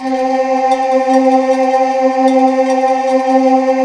Index of /90_sSampleCDs/USB Soundscan vol.28 - Choir Acoustic & Synth [AKAI] 1CD/Partition C/12-LIVES